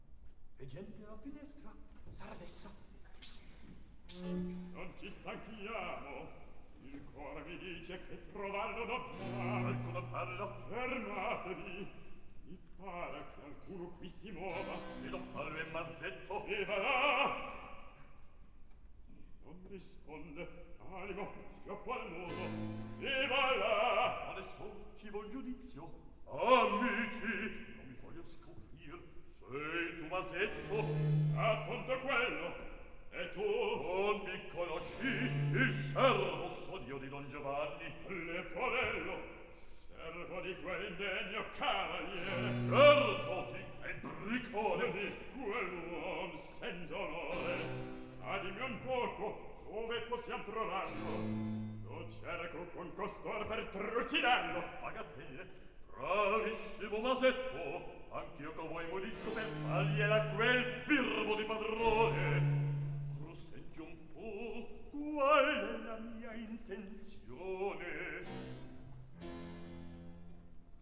Recitativo.